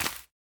Minecraft Version Minecraft Version latest Latest Release | Latest Snapshot latest / assets / minecraft / sounds / block / bamboo / sapling_place6.ogg Compare With Compare With Latest Release | Latest Snapshot
sapling_place6.ogg